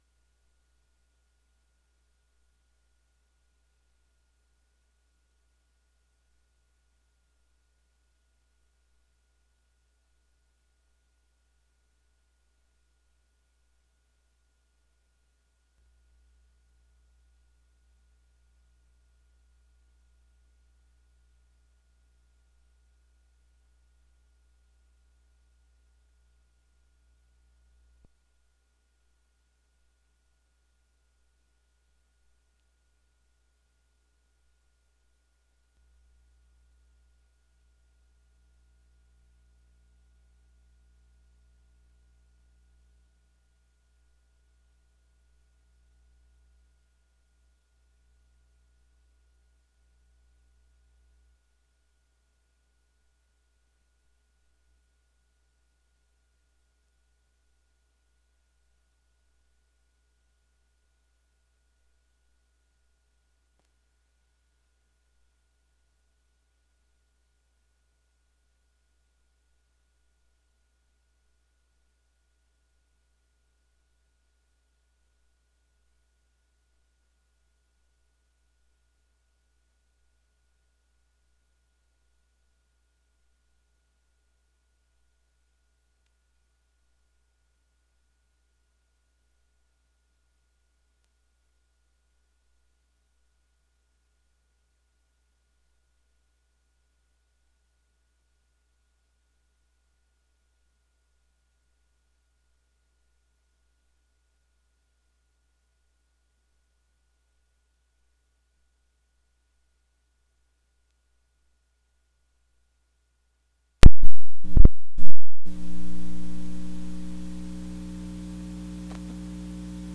Áudio da 1° Sessão Ordinária – 01/01/2017